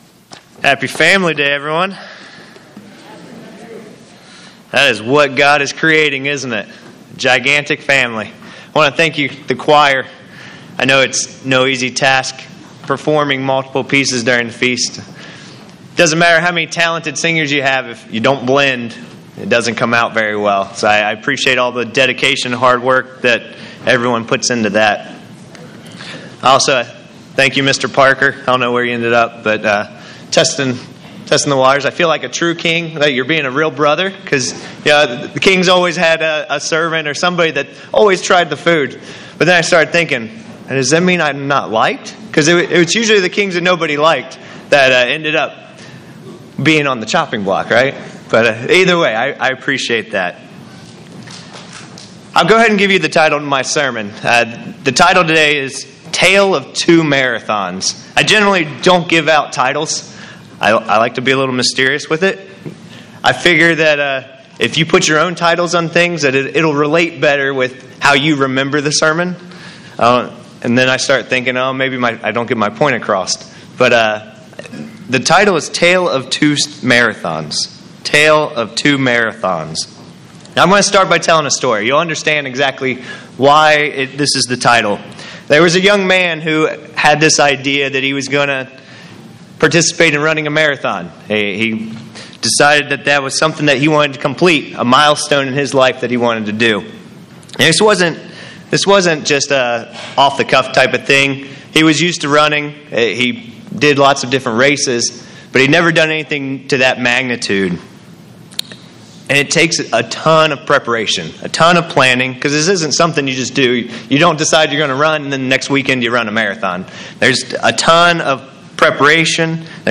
This sermon was given at the Lake Junaluska, North Carolina 2019 Feast site.